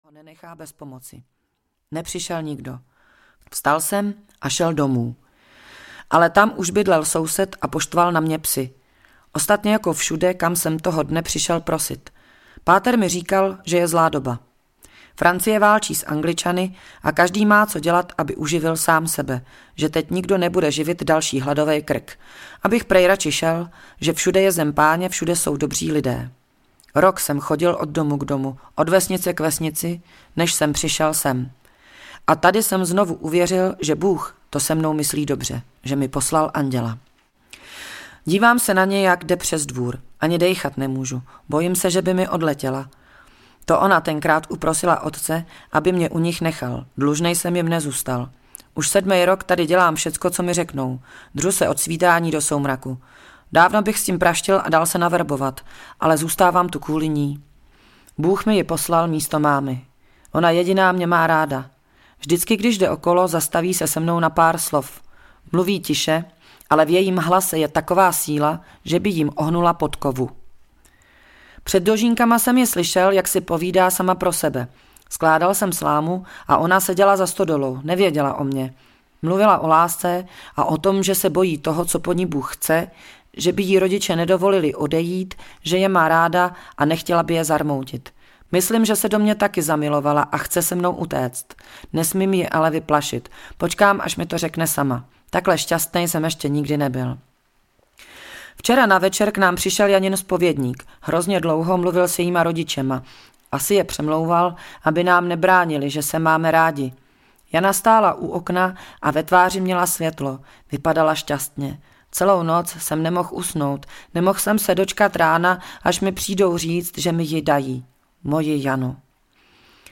Marathon, příběh běžce audiokniha
Ukázka z knihy
• InterpretRadůza